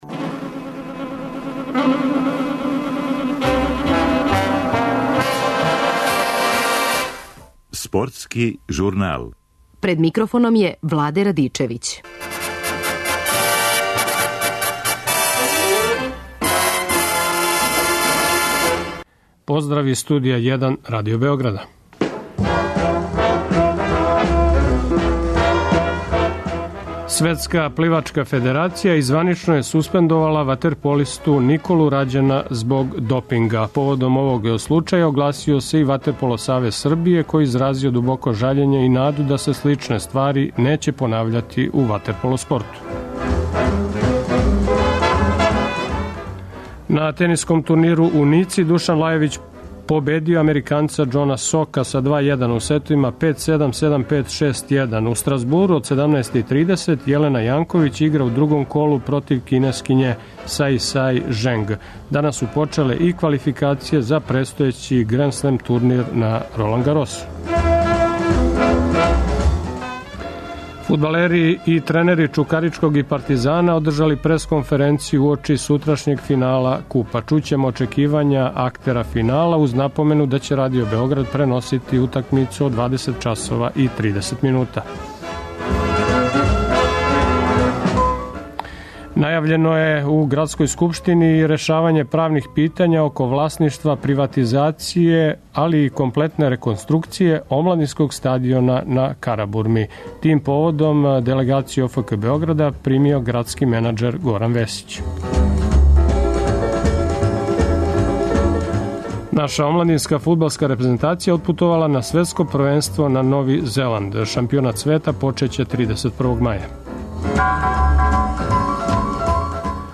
Чућемо очекивања актера финала.